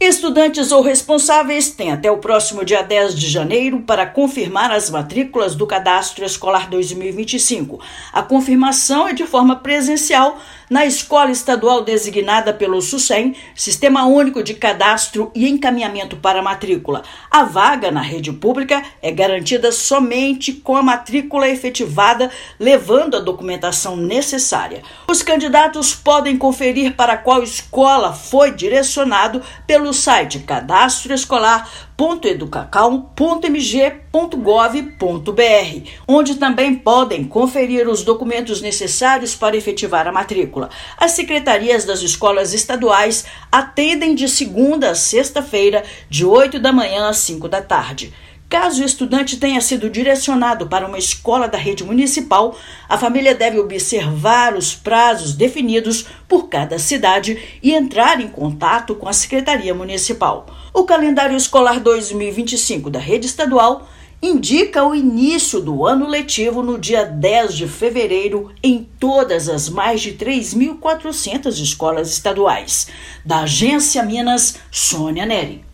Os responsáveis ou estudantes maiores de idade devem apresentar os documentos exigidos para efetivar a matrícula na escola indicada. Ouça matéria de rádio.